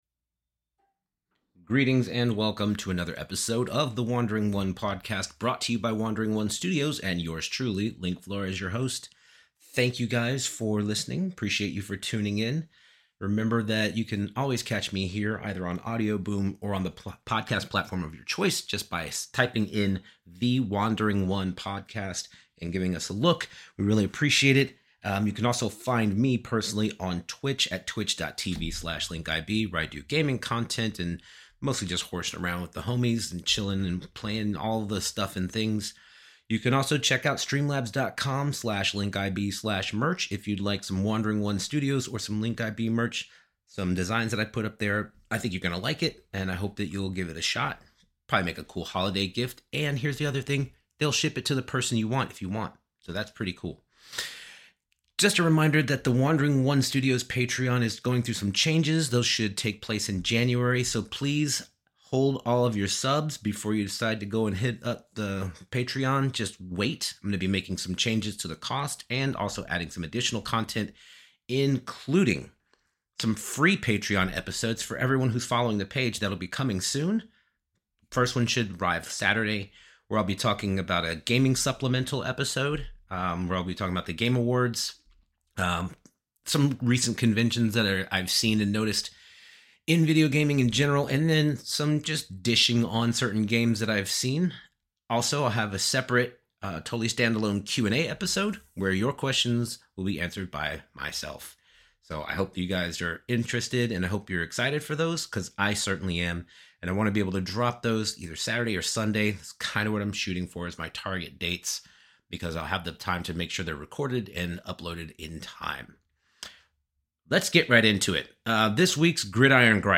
<<< WARNING: May contain adult language and thematic content. Listener discretion is advised. >>>